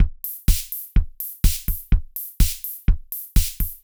IBI Beat - Mix 4.wav